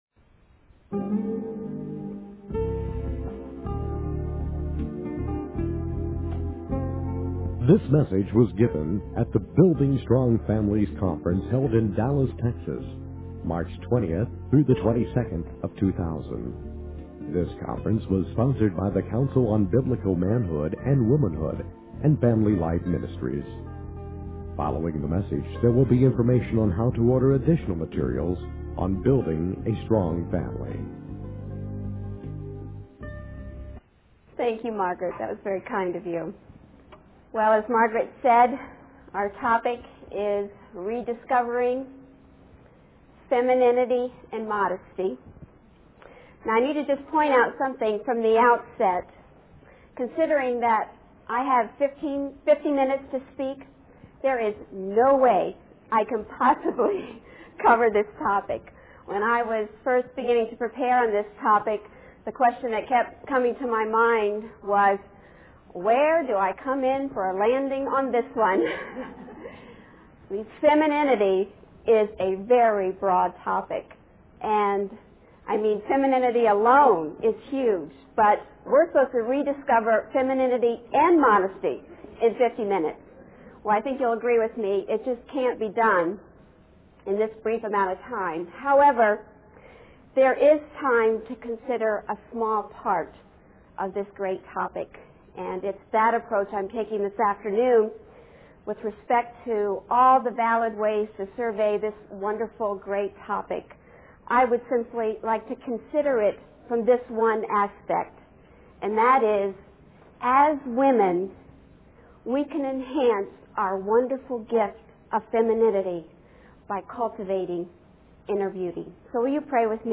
The sermon was given at a conference on building strong families and the topic of rediscovering femininity and modesty is briefly touched upon, with a focus on cultivating inner beauty as women.